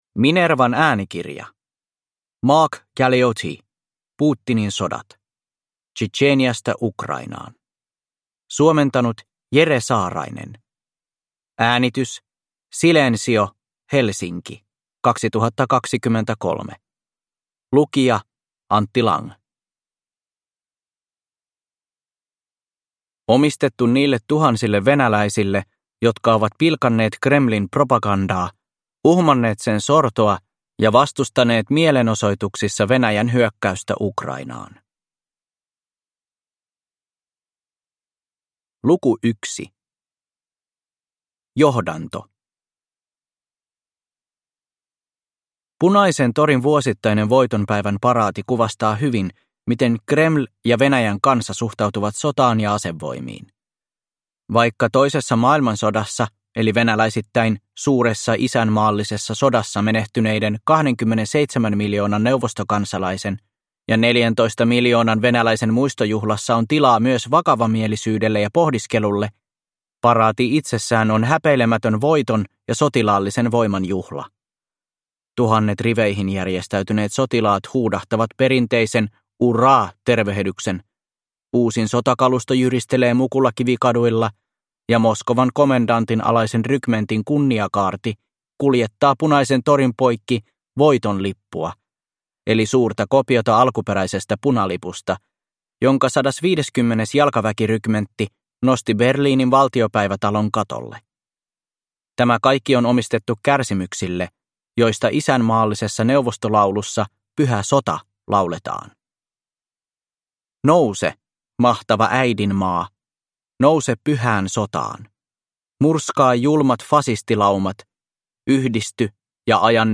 Putinin sodat – Ljudbok – Laddas ner